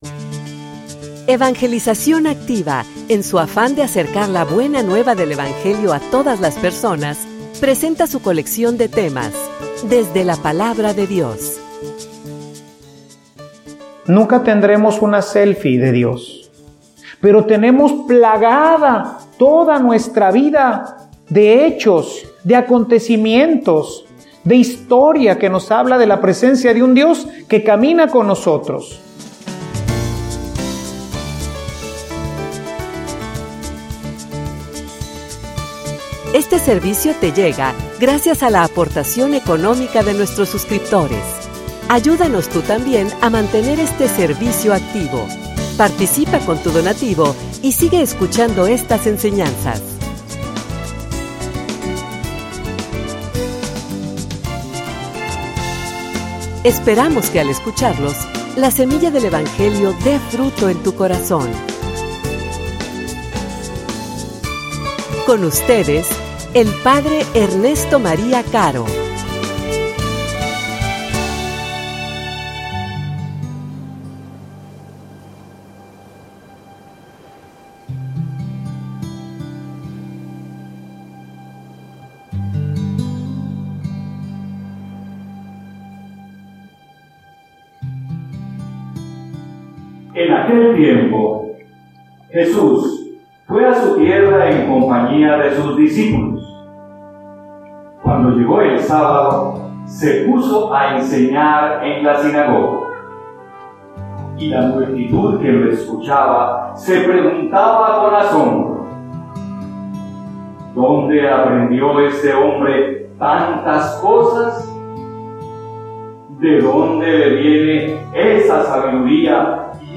homilia_Que_no_te_enganen.mp3